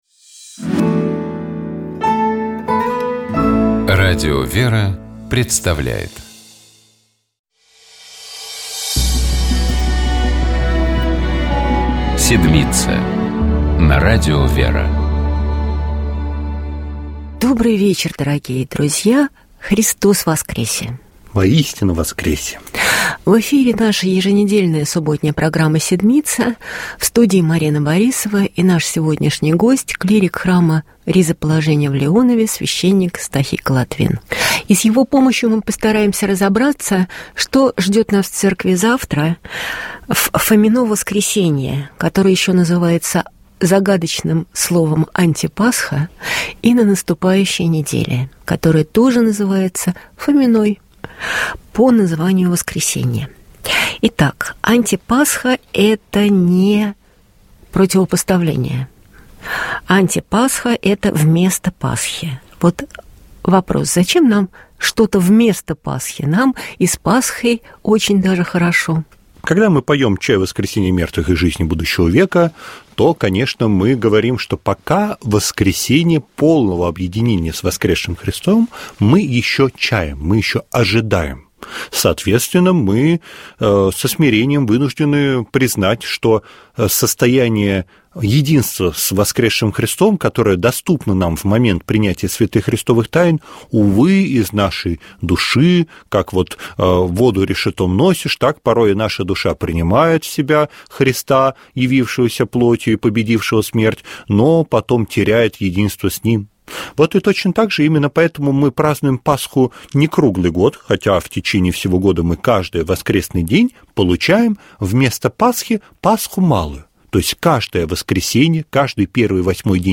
В этом цикле бесед ведущий и гость в студии говорят о смыслах и особенностях богослужения наступающего воскресного дня, а также о важных церковных датах предстоящей недели. В каждом выпуске разбираются Евангельские и Апостольские чтения, звучащие на воскресном богослужении, а также обсуждаются праздники, дни памяти святых, которые Церковь отмечает на наступающей неделе.